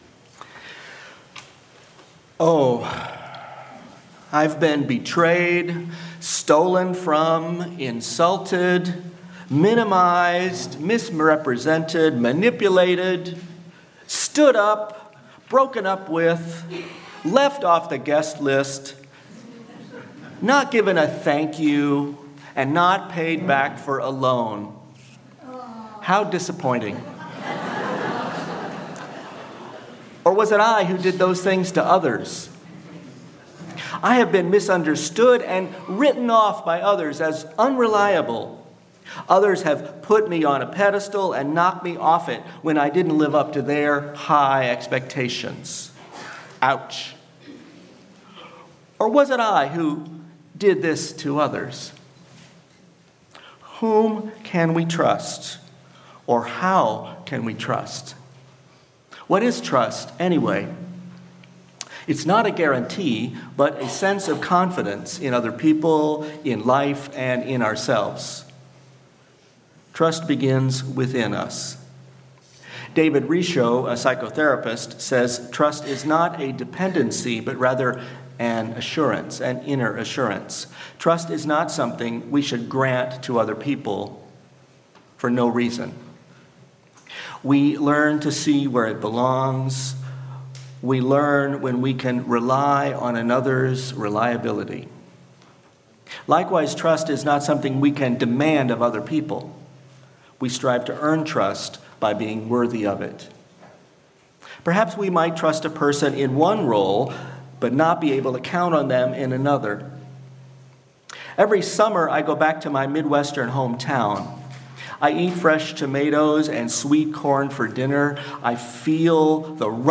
Sermon-Daring-to-Trust.mp3